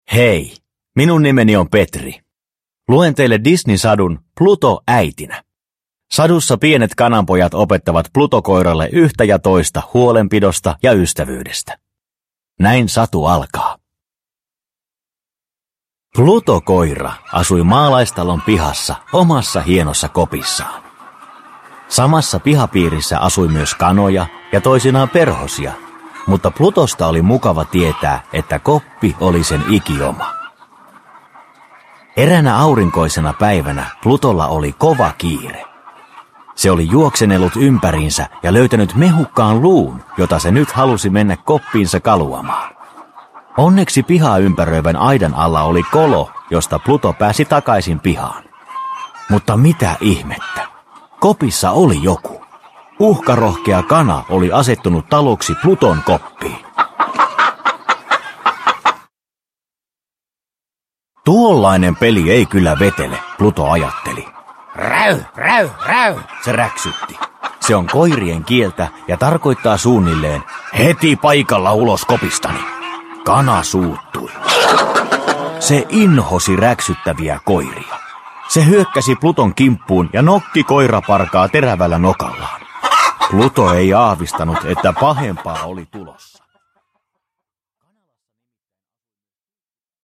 Pluto äitinä – Ljudbok – Laddas ner